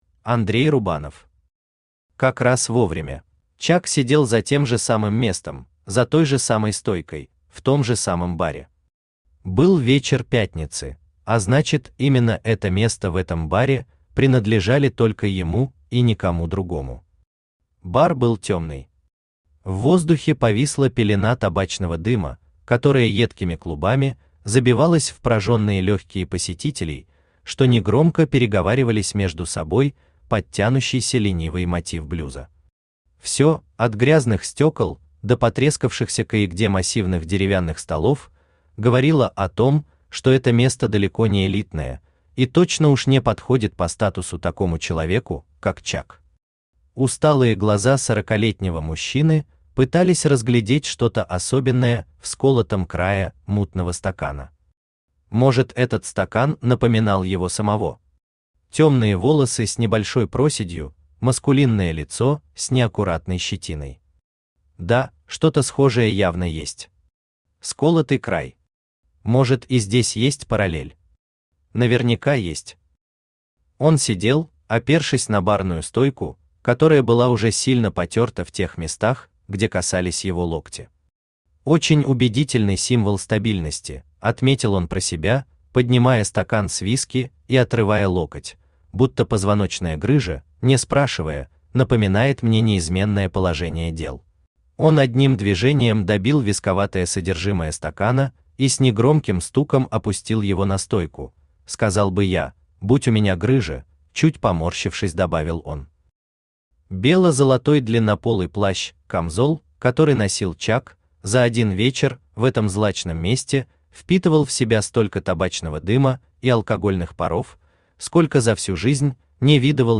Аудиокнига Как раз вовремя | Библиотека аудиокниг
Aудиокнига Как раз вовремя Автор Андрей Рубанов Читает аудиокнигу Авточтец ЛитРес.